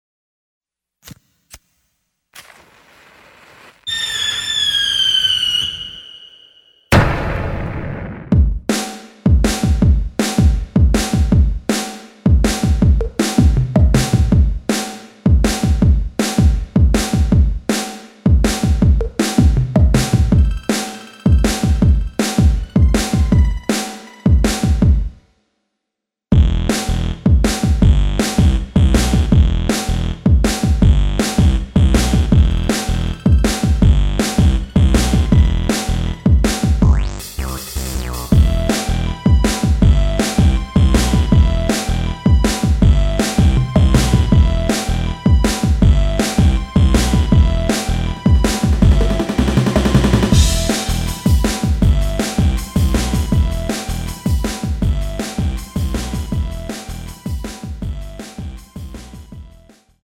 Em
앞부분30초, 뒷부분30초씩 편집해서 올려 드리고 있습니다.
중간에 음이 끈어지고 다시 나오는 이유는